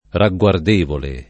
ragguardevole [ ra ggU ard % vole ] agg.